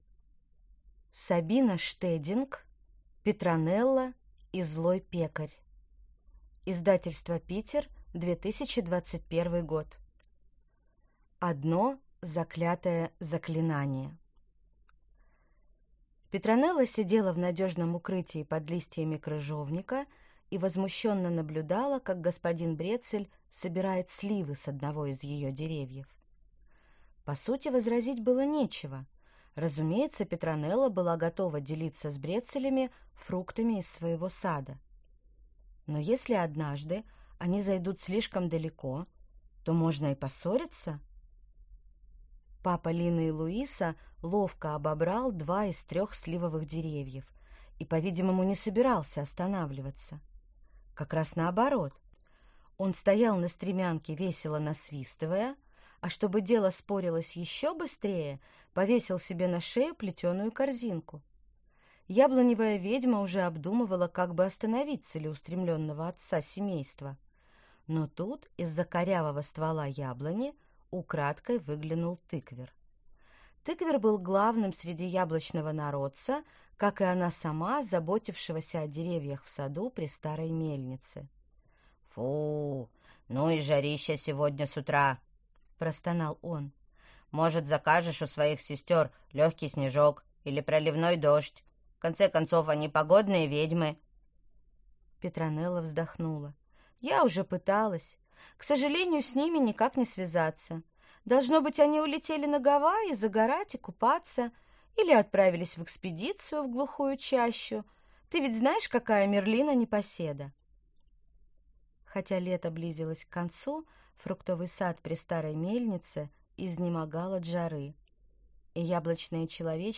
Аудиокнига Петронелла и злой пекарь | Библиотека аудиокниг